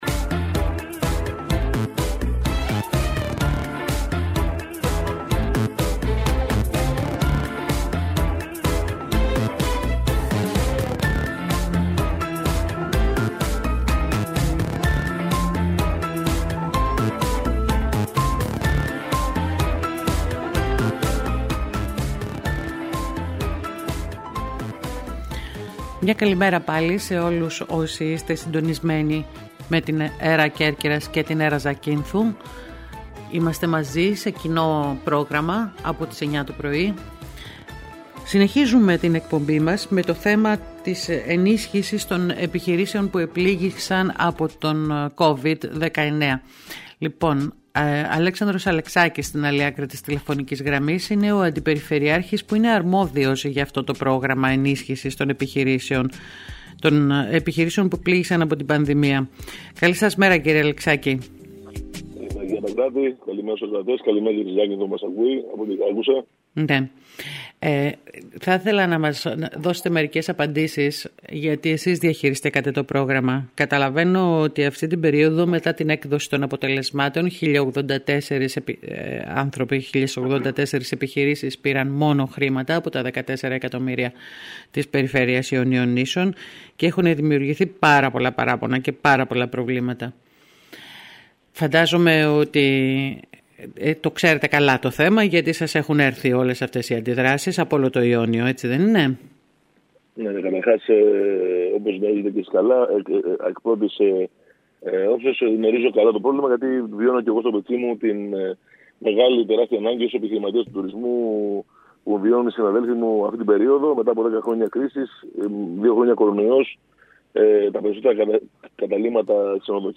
Μιλώντας σήμερα στην ΕΡΑ ΚΕΡΚΥΡΑΣ ο κ. Αλεξάκης τόνισε ότι δεν φταίει η Περιφέρεια για τους όρους και τους κανόνες βαθμολόγησης των επιχειρήσεων αλλά ο τρόπος που λειτουργεί το ΕΣΠΑ.